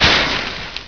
flare1.wav